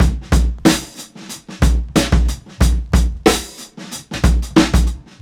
• 92 Bpm Breakbeat Sample B Key.wav
Free drum loop - kick tuned to the B note. Loudest frequency: 1116Hz
92-bpm-breakbeat-sample-b-key-ze4.wav